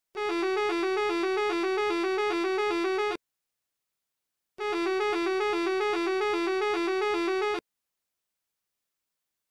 بوري ماركو 3 ماصورة تشكيلة 24 فولت إيطالي